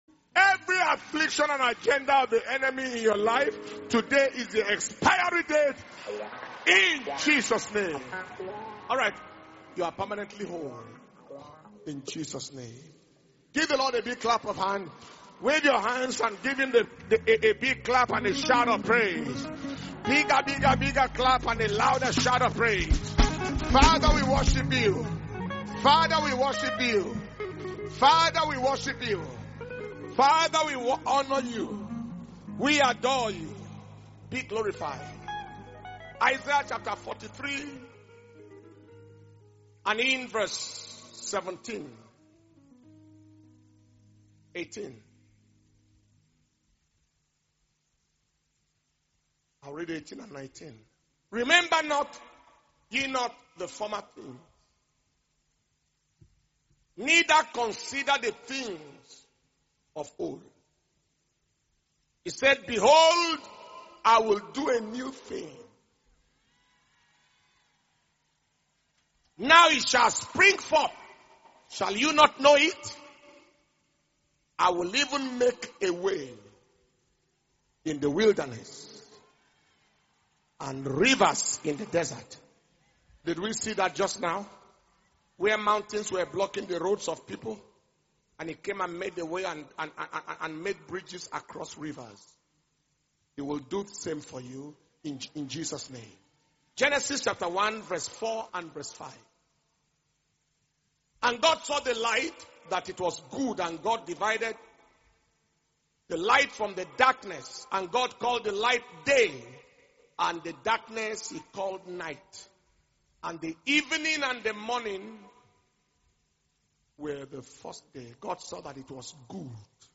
Power Communion Service – Wednesday, 30th June 2021